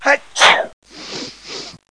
00147_Sound_NiesSchnief.mp3